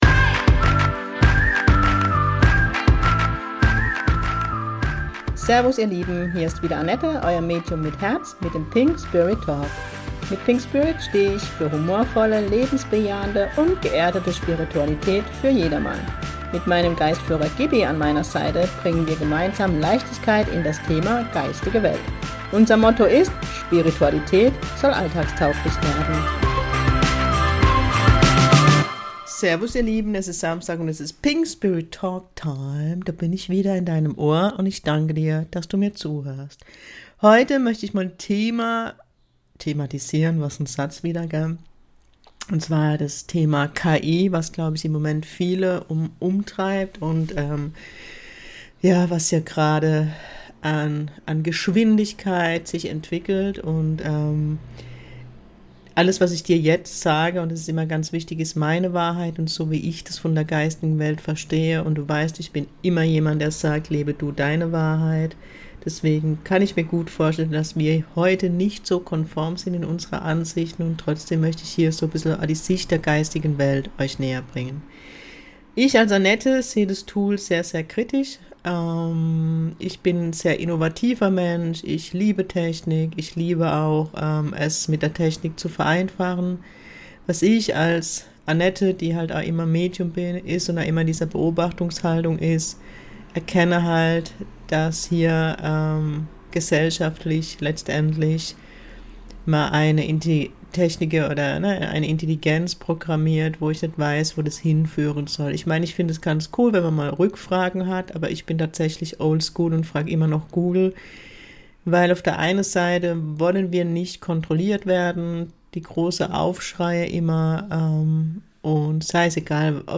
In den Folgen geht es um die Themen Spiritualität, Sensitivität, Medialität, mein Geistführer und ich, meine Arbeit als Medium, Interviews mit Menschen die mich auf dem Weg zum Medium begleiten. Und das alles mit ganz viel Leichtigkeit und meinem Kurpfälzer Charme.